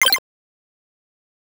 Chiptune Sample Pack
8bit_FX_C_03_01.wav